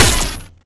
SFX item_card_lightning_shooting.wav